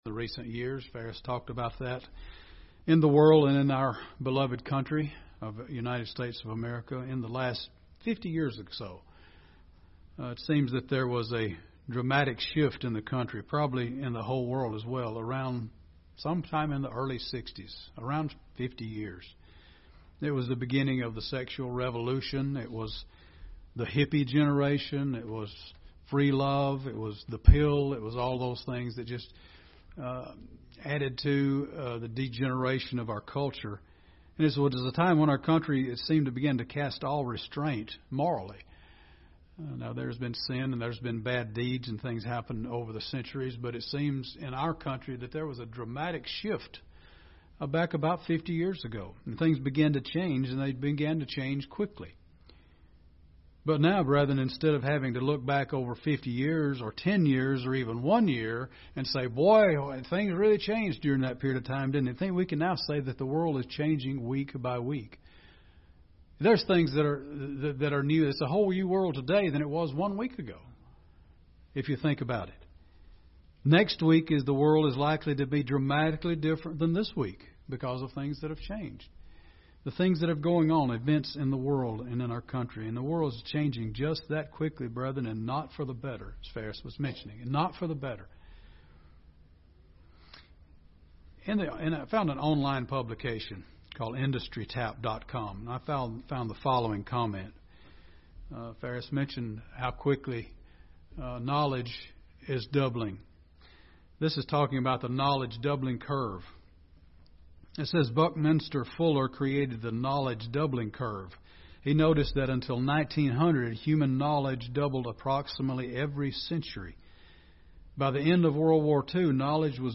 Given in Gadsden, AL Birmingham, AL
UCG Sermon Studying the bible?